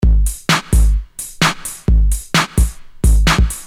Free MP3 electro drumloops soundbank 2
Electro rythm - 130bpm 25